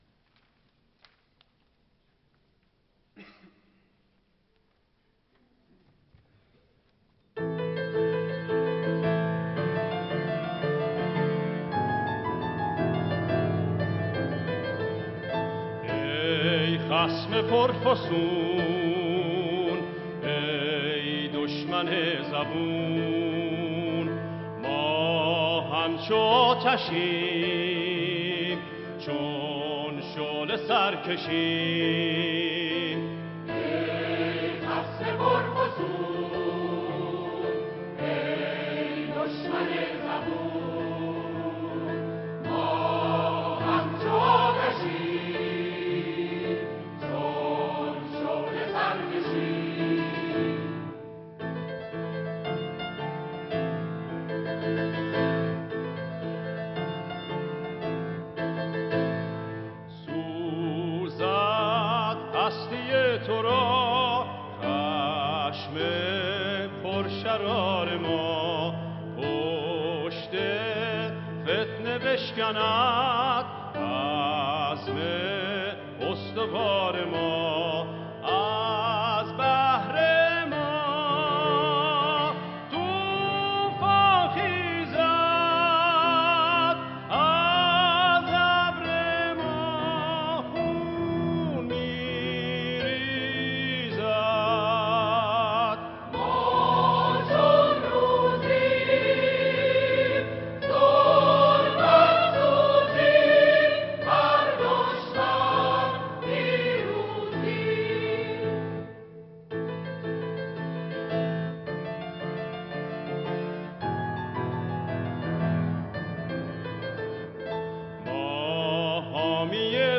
در تالار وحدت اجرا شده است